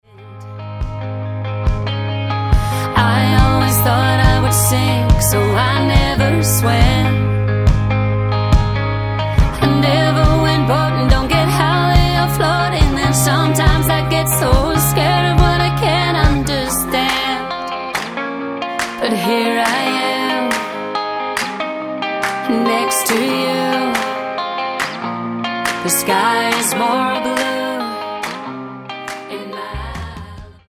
Tonart:Ab Multifile (kein Sofortdownload.
Die besten Playbacks Instrumentals und Karaoke Versionen .